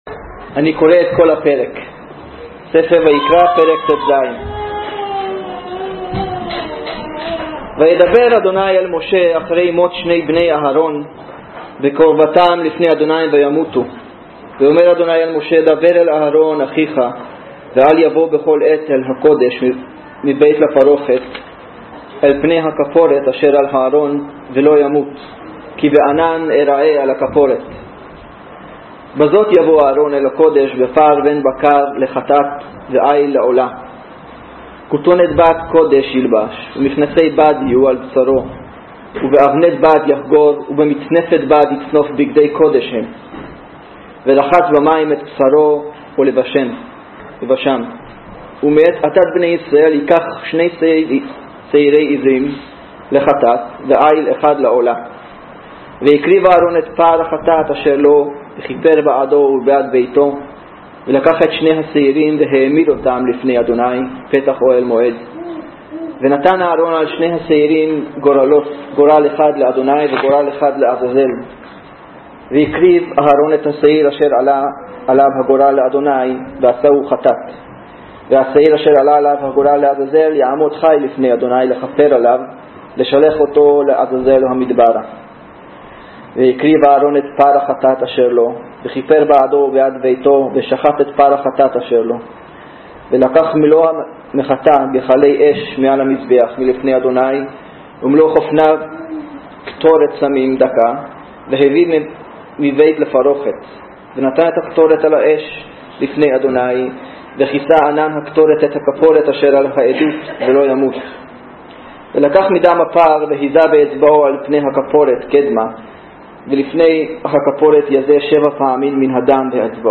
פברואר 12, 2017 דרשות לפי נושאים